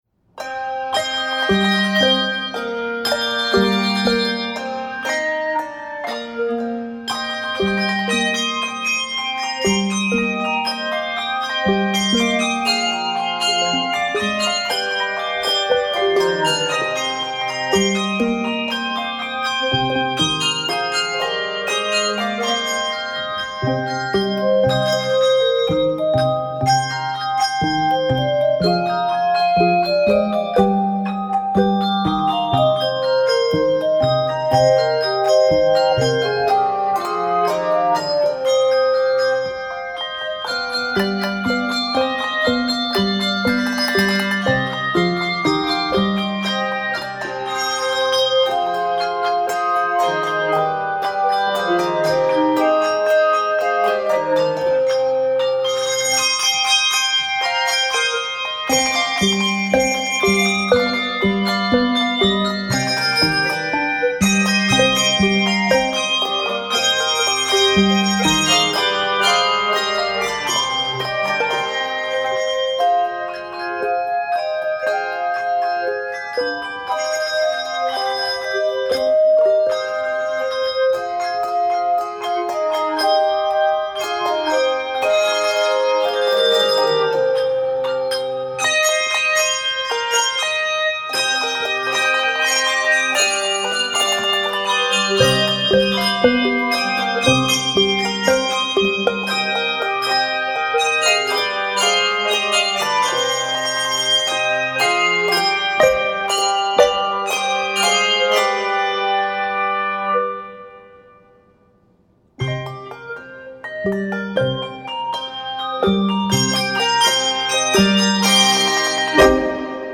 - Handbells